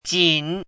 “卺”读音
jǐn
卺字注音：ㄐㄧㄣˇ
国际音标：tɕin˨˩˦
jǐn.mp3